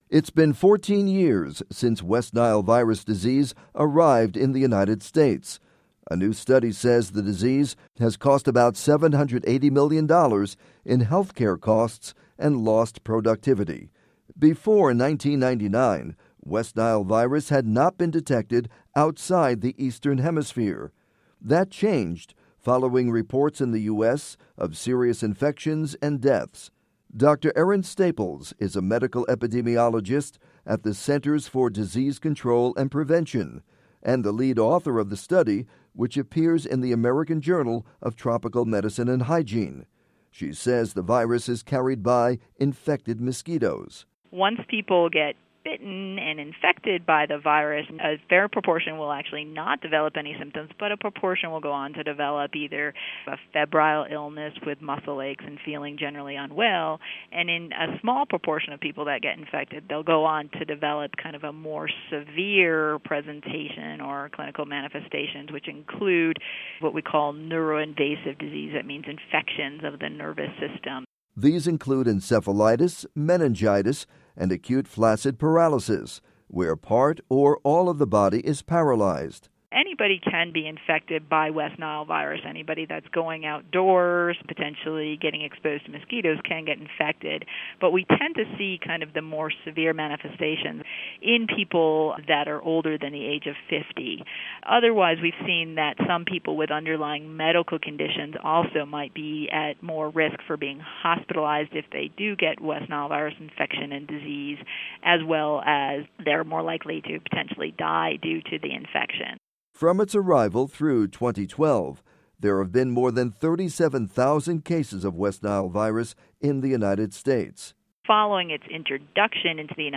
report on West Nile Virus in U.S.